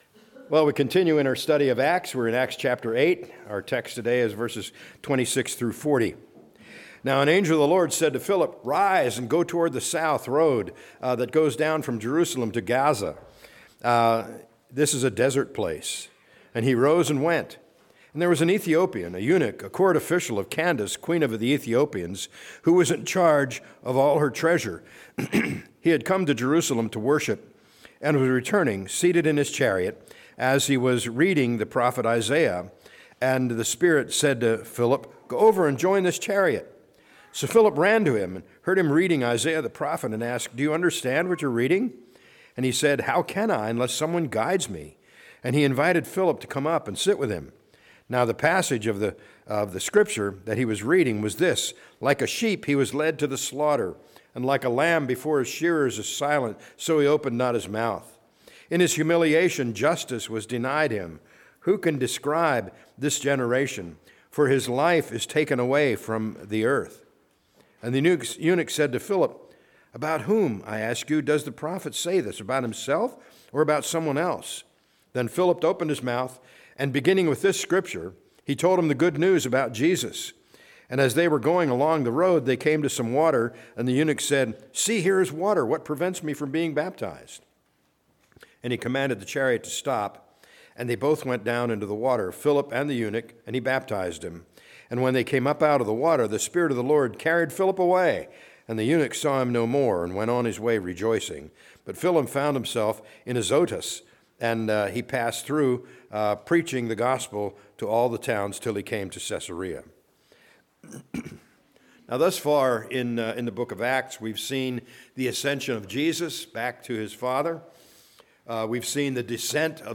A message from the series "Parables of Jesus."